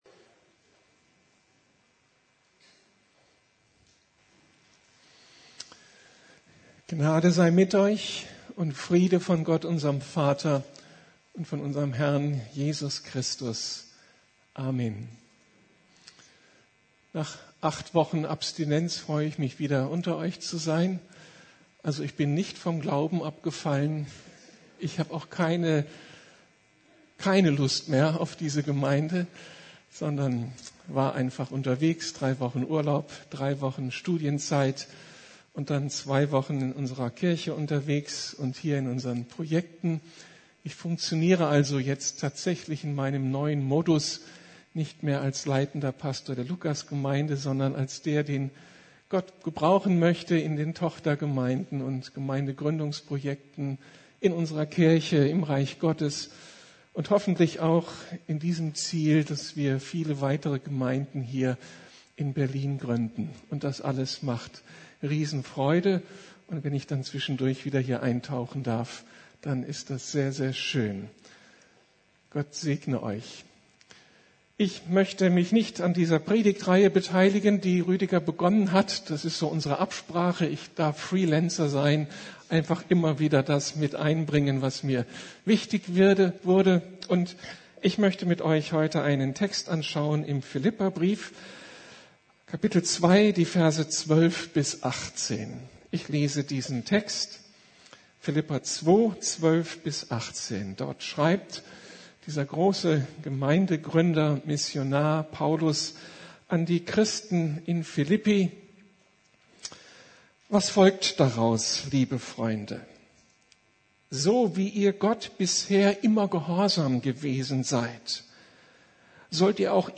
Unsere Berufung: Leuchten wie Sterne in der Nacht ~ Predigten der LUKAS GEMEINDE Podcast